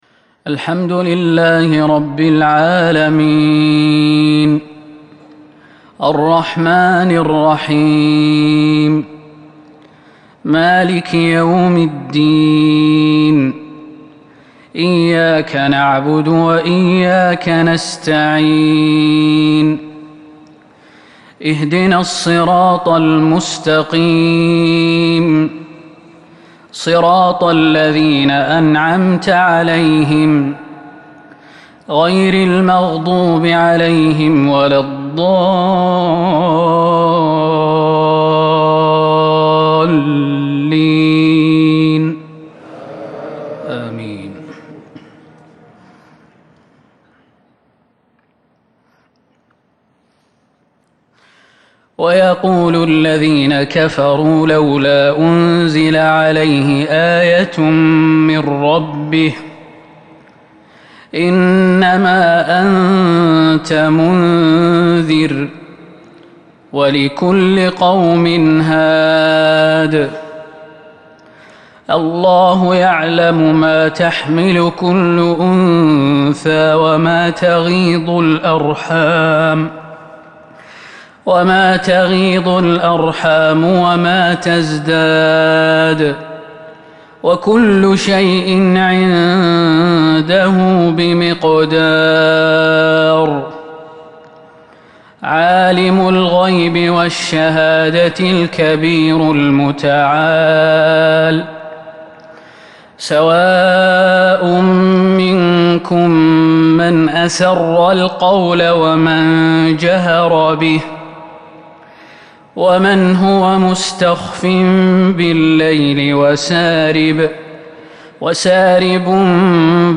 صلاة العشاء من سورة الرعد ١٩/١/١٤٤٢ isha prayer from surah Al-raad 7/9/1442 > 1442 🕌 > الفروض - تلاوات الحرمين